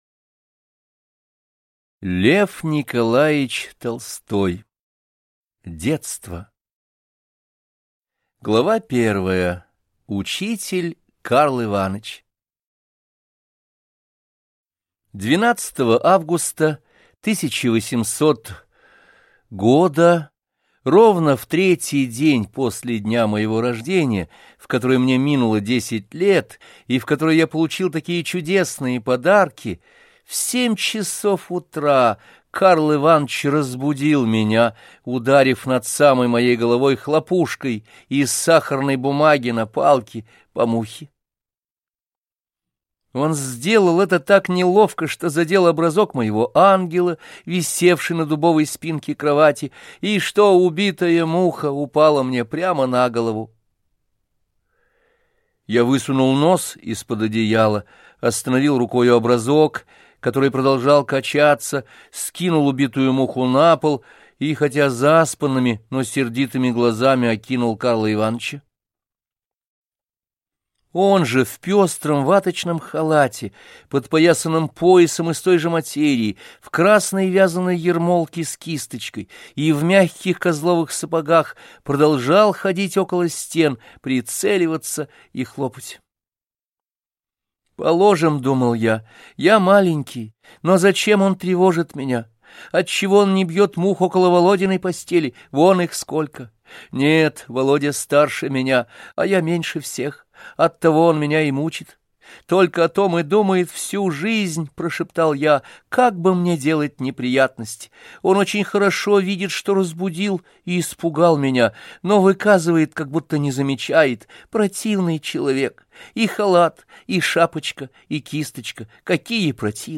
Аудиокнига Детство. Отрочество. Юность - купить, скачать и слушать онлайн | КнигоПоиск